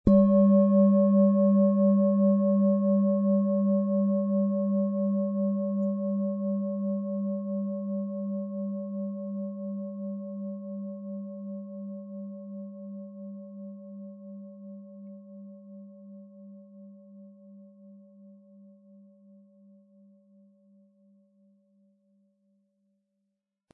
Tageston
Ihr Klang trägt die Stille vergangener Zeiten in sich - ein stilles Flüstern, das uns innehalten lässt.
Im mittleren Bereich klingt in dieser Schale außerdem der Merkur-Ton mit.